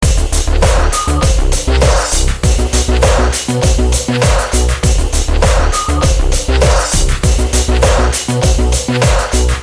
铁路列车》 《铁路列车》3
描述：编辑循环的火车声音。
标签： 火车 节奏
声道立体声